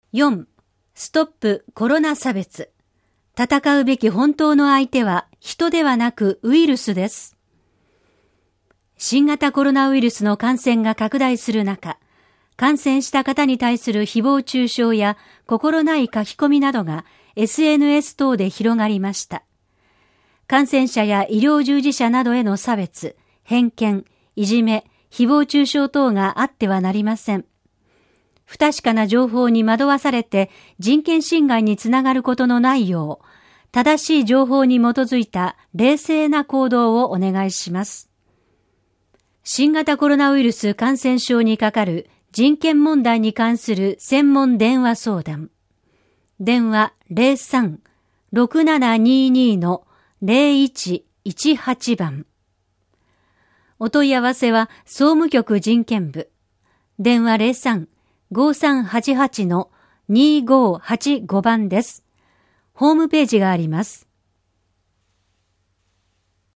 「広報東京都音声版」は、視覚に障害のある方を対象に「広報東京都」の記事を再編集し、音声にしたものです。